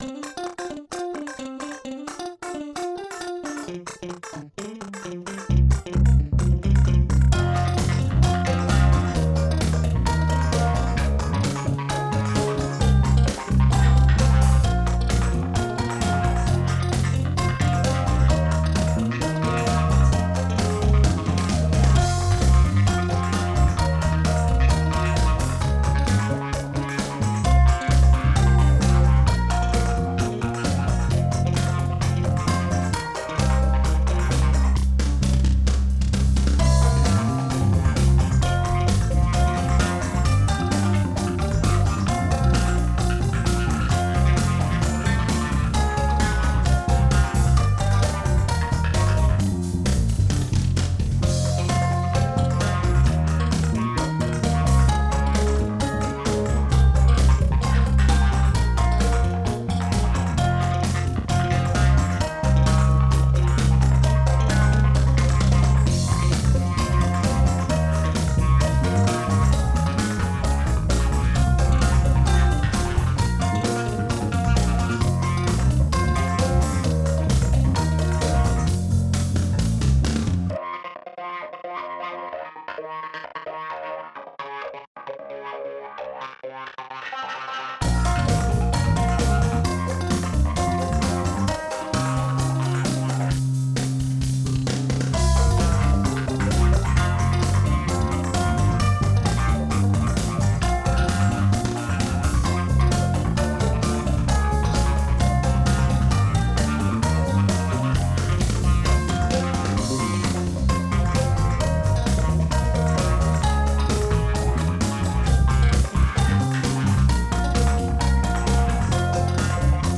Genre: Hip-Hop/Rap